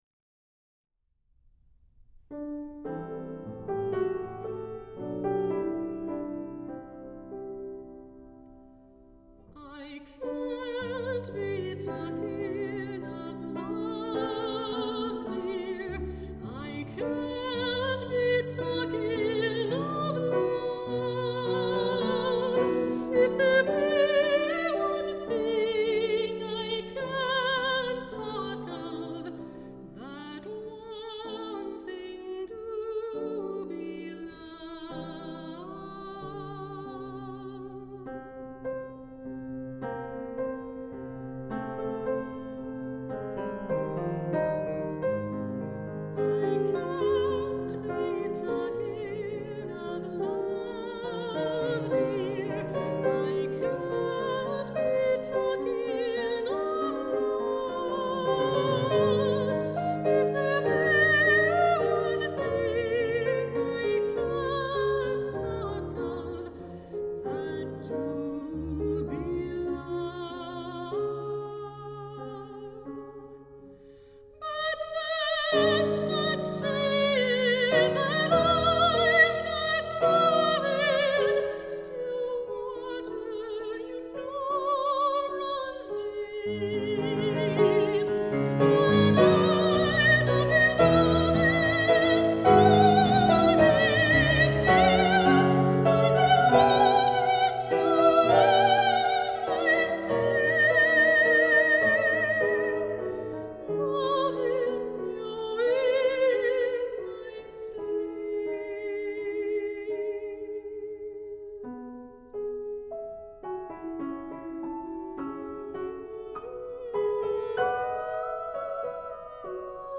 Medium, piano (c. 6:30)